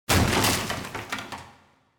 breakBox.m4a